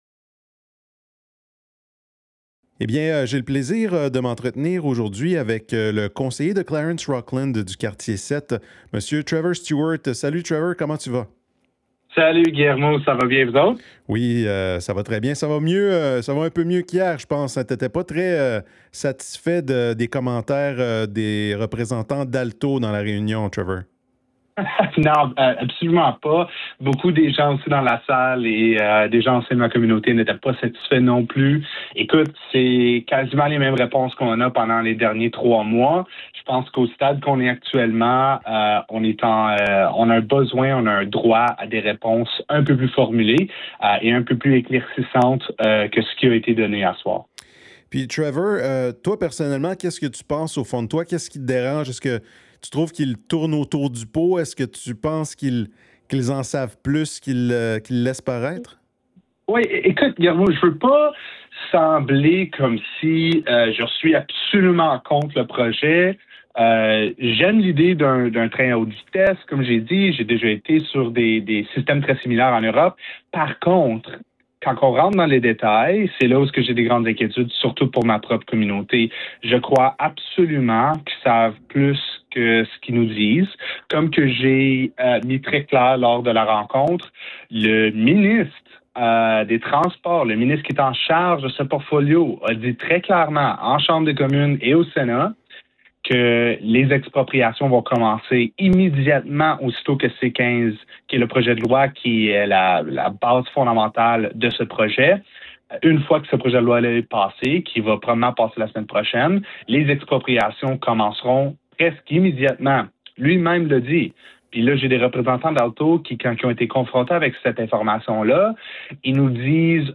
Je me suis entretenue avec le conseiller municipal de Clarence-Rockland du quartier 7, Trevor Stewart, à propos d’Alto, qui a fait une apparition lors du dernier conseil municipal du 12 mars 2026.